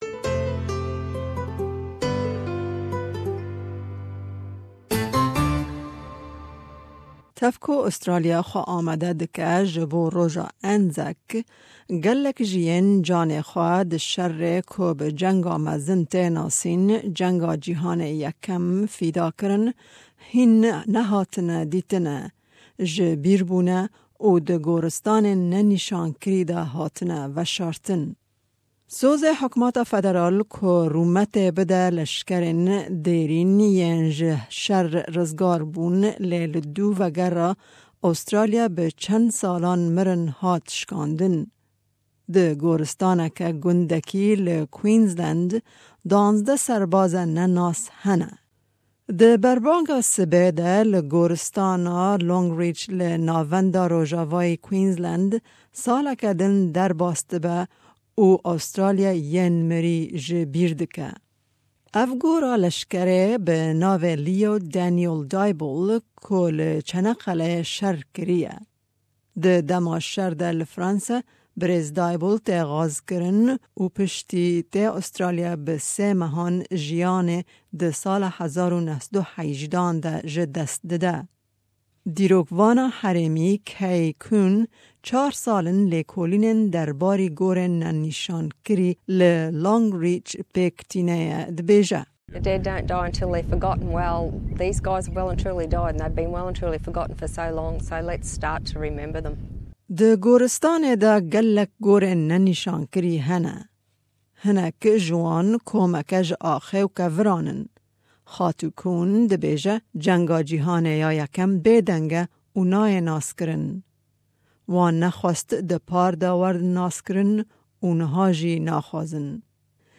Tev ku Australya xwe amade dike jibo Roja Anzac, gelek ji yên canê xwe di sherê ku bi Cenga Mezin tê nasîn, şerê Cîhanê Yekê fîda kirin hîn nehatine dîtine - ji bîr bûne û di gortinistanên ne-nîshan kirî de hatine veshartin. Raport bi Kurdî û Îngilîzî.